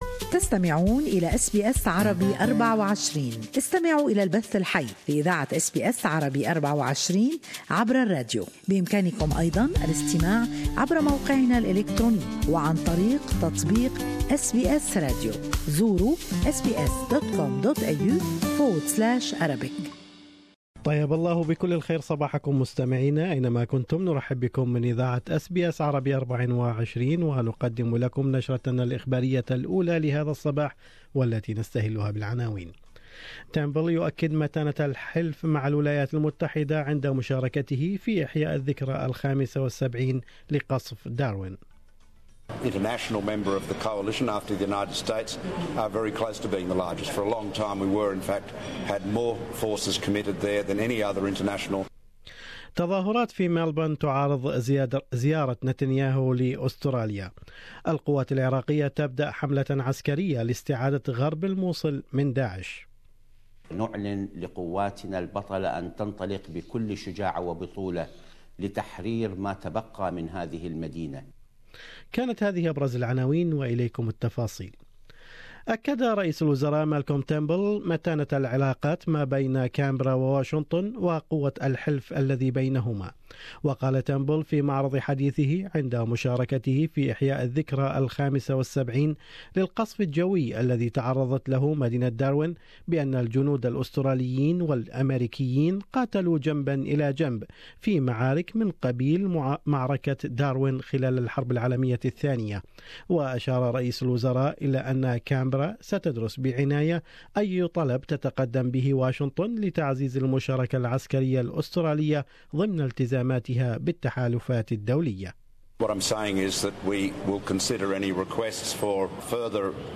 News Bulletin 20-2-17